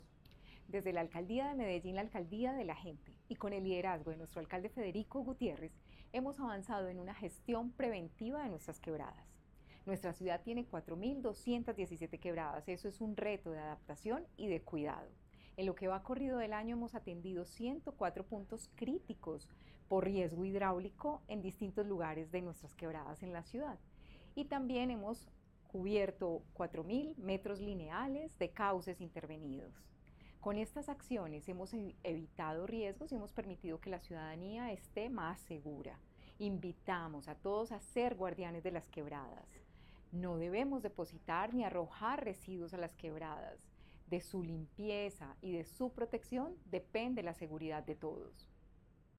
Declaraciones-secretaria-de-Medio-Ambiente-Marcela-Ruiz-1.mp3